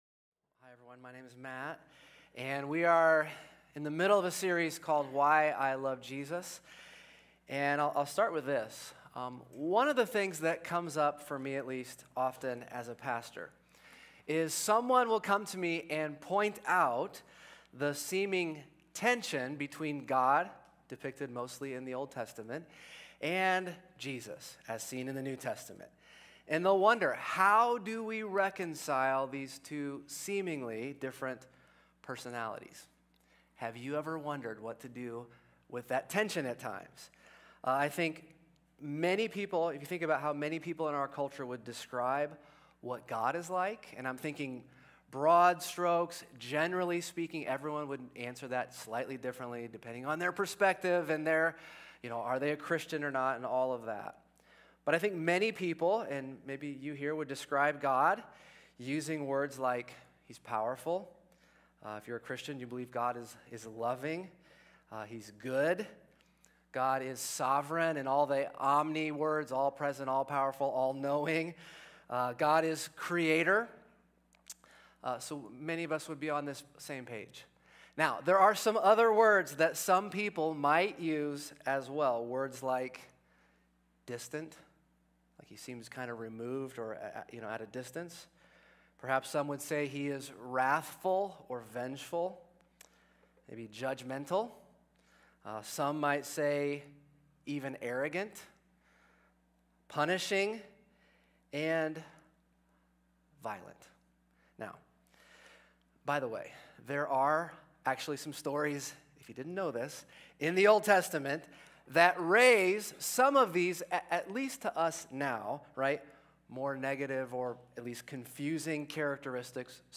A message from the series "Why I Love Jesus."